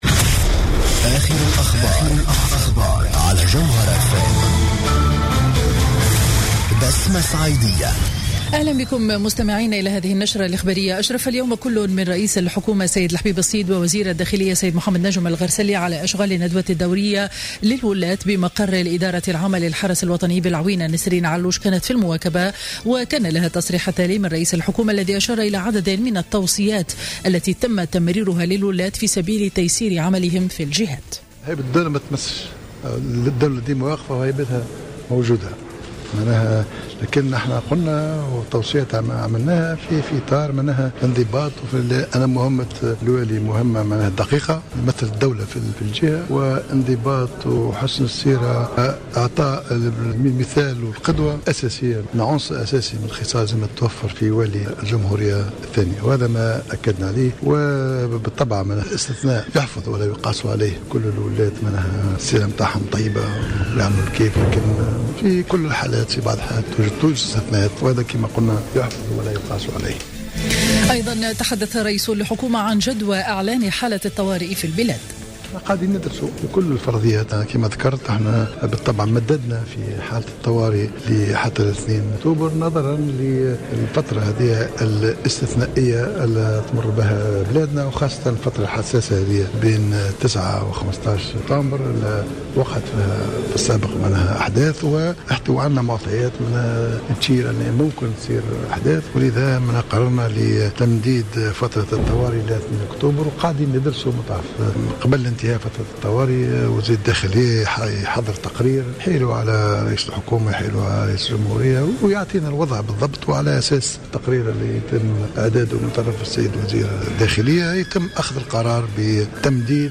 نشرة أخبار منتصف النهار ليوم الأحد 13 سبتمبر 2015